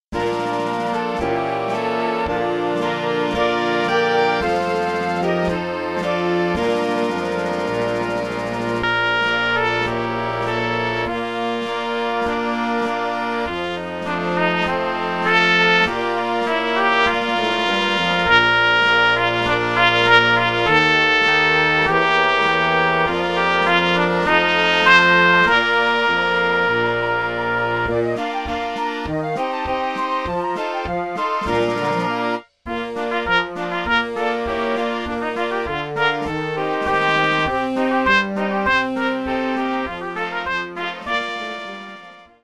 Trąbka solo z towarzyszeniem orkiestry dętej